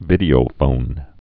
(vĭdē-ō-fōn)